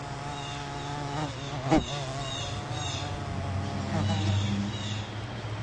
Descarga de Sonidos mp3 Gratis: abejorro.
abejorros-abejorro-.mp3